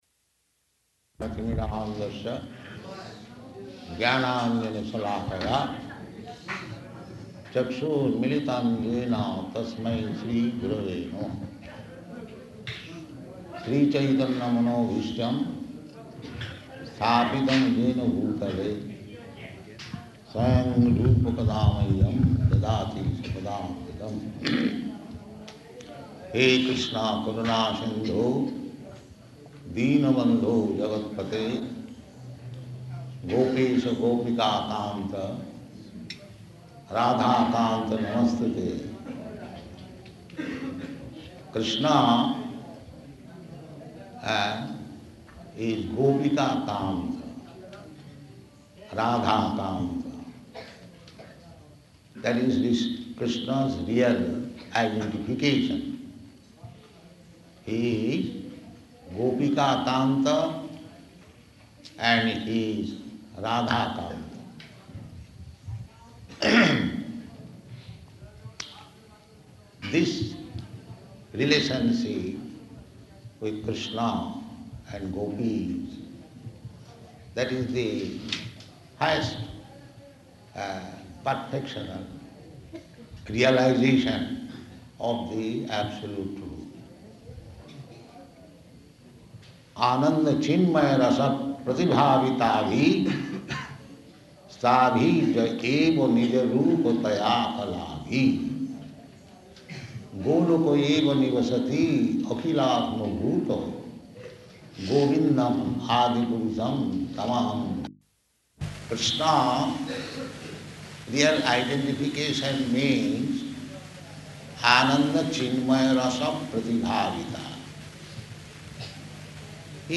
Type: Lectures and Addresses
Location: Māyāpur